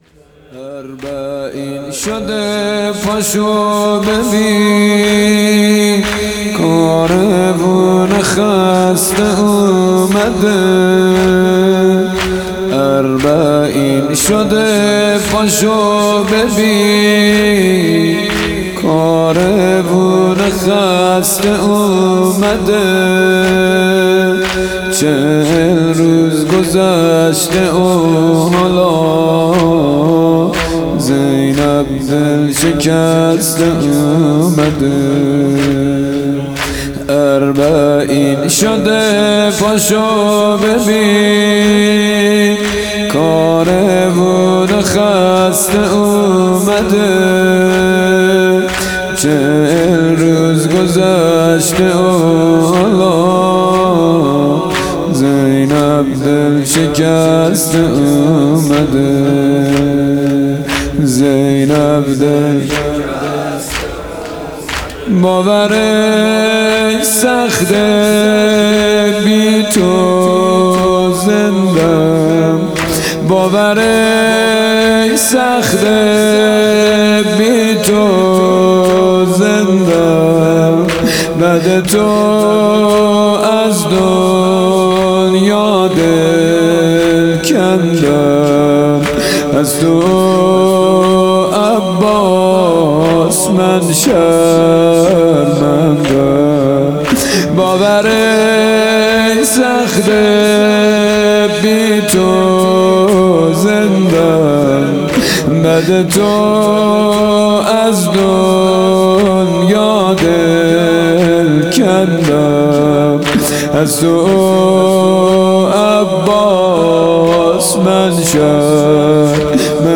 مراسم شام اربعین